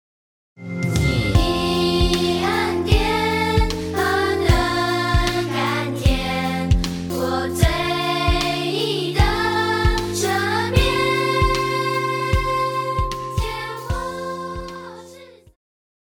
流行-合唱,童声
乐团
圣歌,流行音乐,教会音乐
歌唱曲
声乐与伴奏
有主奏
有节拍器